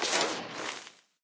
death.ogg